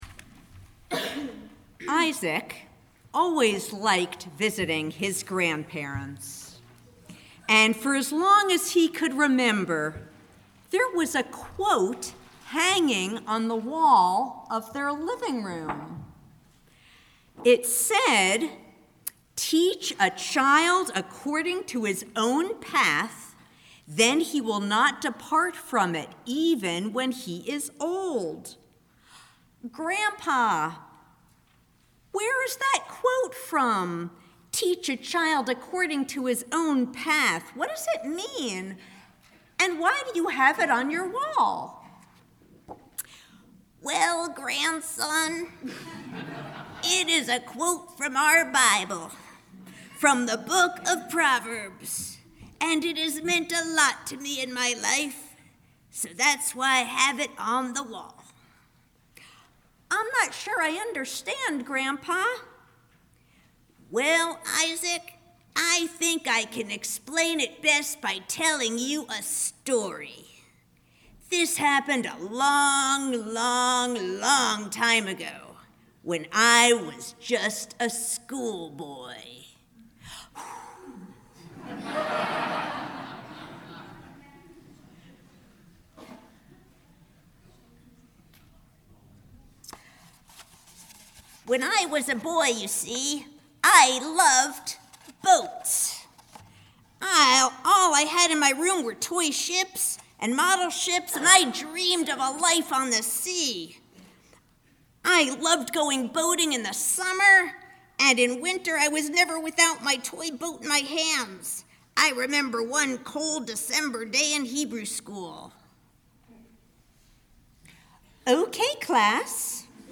Shabbat Sermon – May 04, 2018